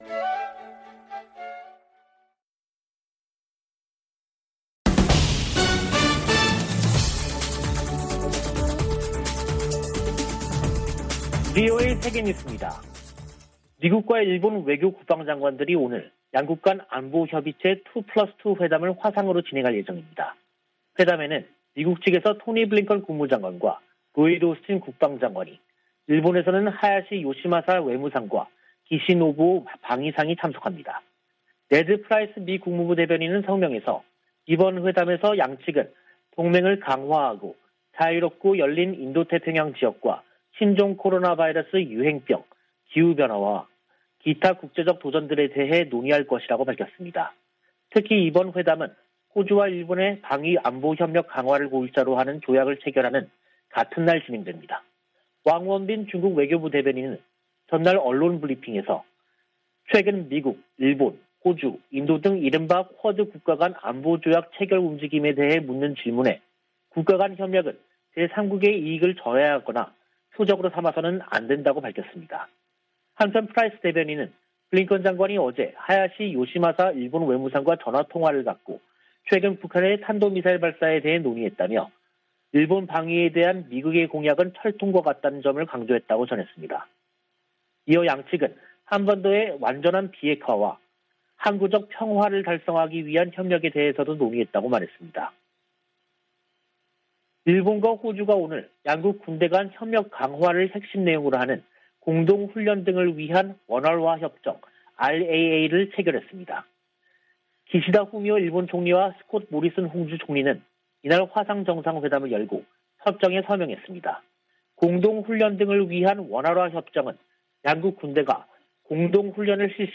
VOA 한국어 간판 뉴스 프로그램 '뉴스 투데이', 2022년 1월 6일 2부 방송입니다. 북한은 5일 쏜 단거리 발사체가 극초음속 미사일이었다고 밝혔습니다. 토니 블링컨 미국 국무장관이 북한의 새해 첫 미사일 도발을 규탄했습니다. 1월 안보리 의장국인 노르웨이는 북한의 대량살상무기와 탄도미사일 개발에 우려를 표시했습니다.